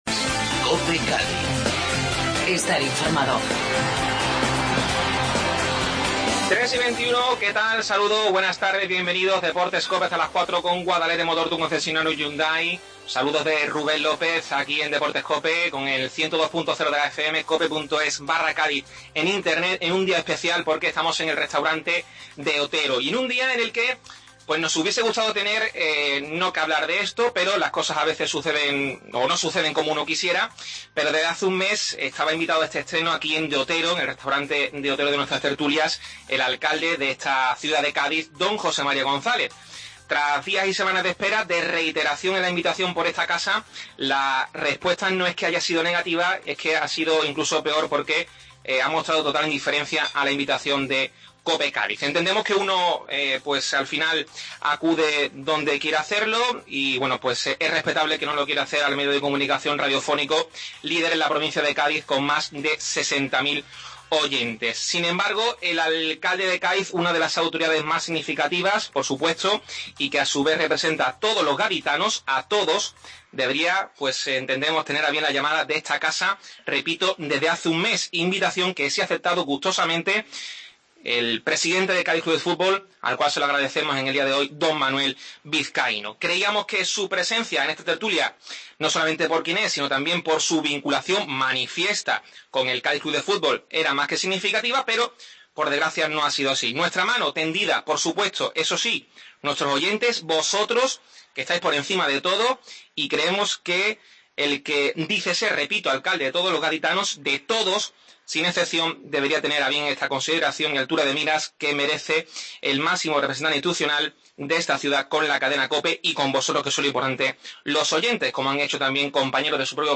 Desde el Restaurante De Otero inauguramos la primera tertulia de la temporada